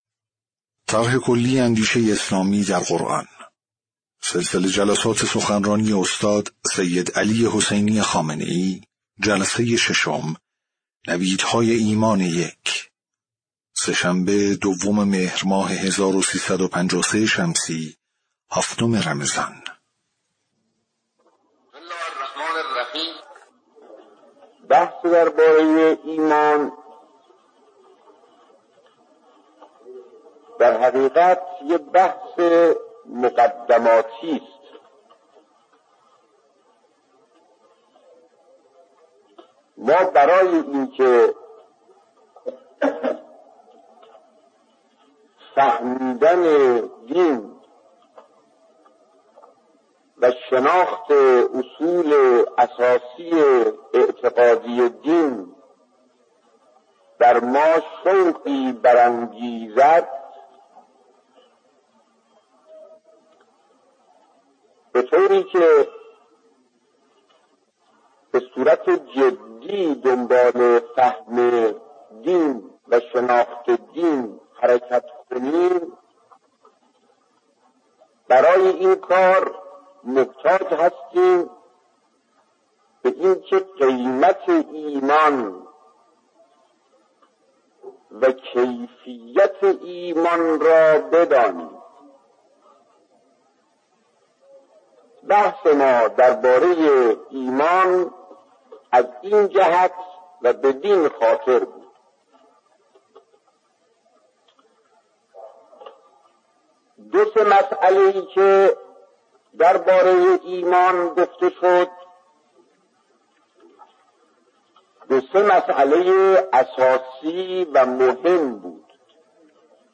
صوت/ جلسه‌ ششم سخنرانی استاد سیدعلی‌ خامنه‌ای رمضان۱۳۵۳
سخنرانی قدیمی